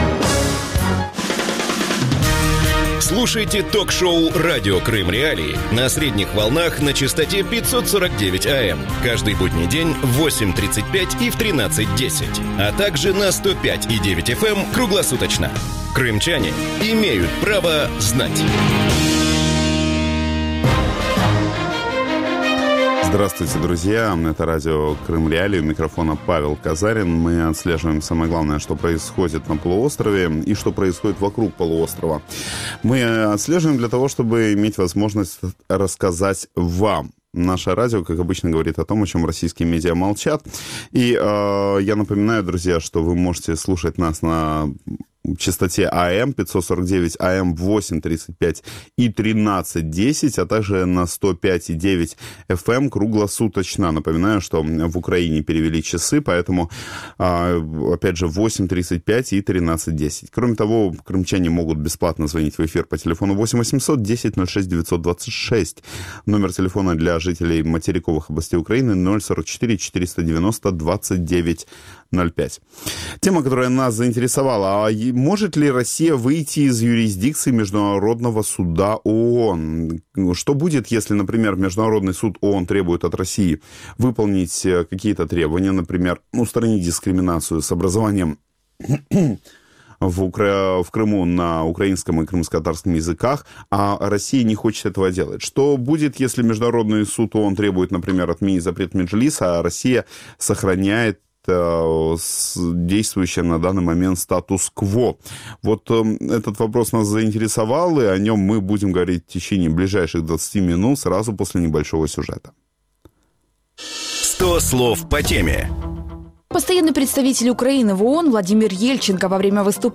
Гости эфира: Борис Бабин постоянный представитель президента Украины в Крыму
Владимир Василенко юрист-международник, бывший судья Международного уголовного суда.